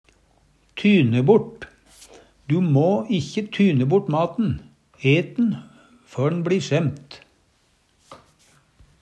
tyne bort - Numedalsmål (en-US)